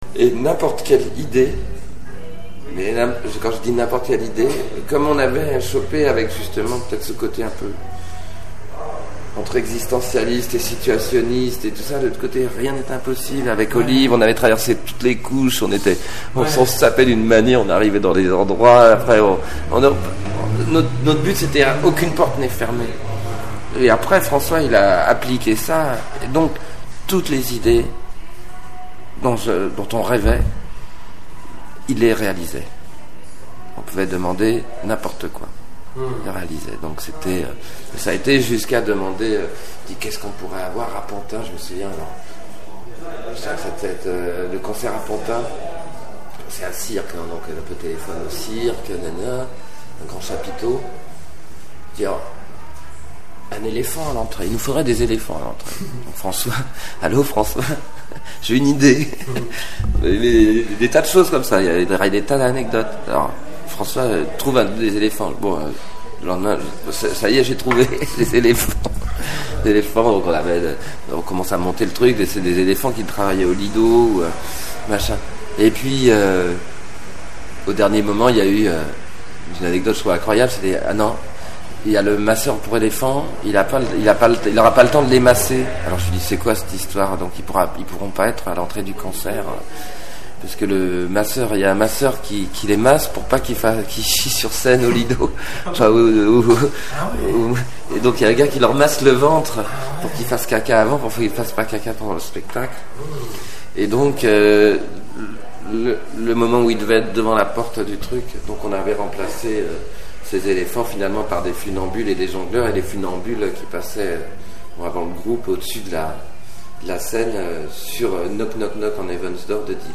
Voici quelques extraits d'interviews de Jean-Louis Aubert menées pour la biographie de Téléphone...